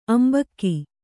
♪ ambakki